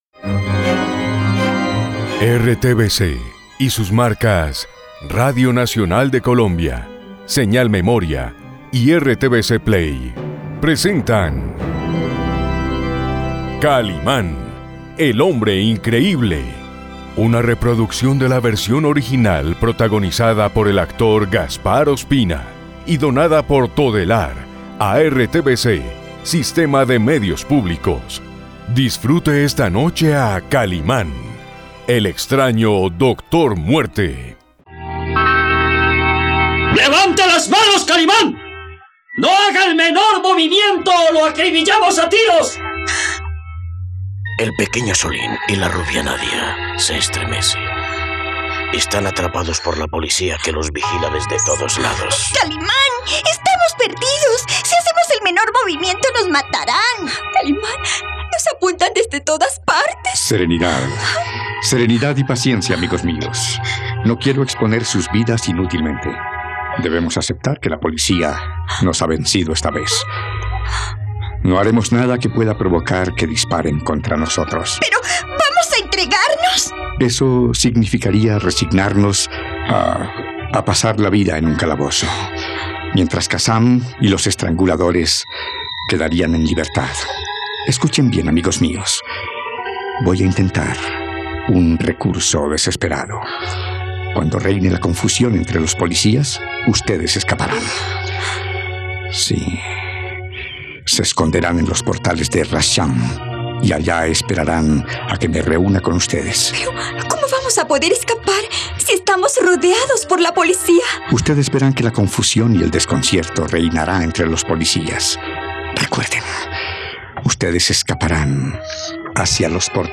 ..Radionovela. El hombre increíble enfrenta al capitán Gary en las oscuras y caóticas calles de Calcuta.